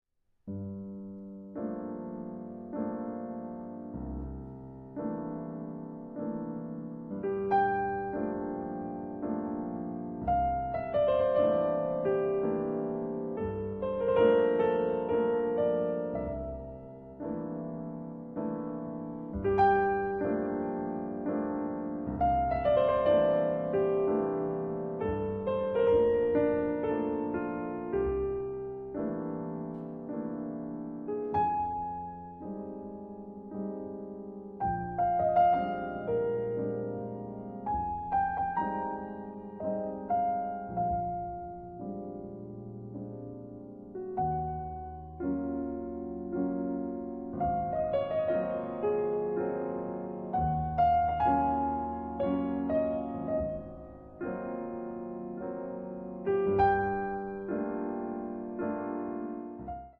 Ravens Sutro Heights Park, San Sound Effects Free Download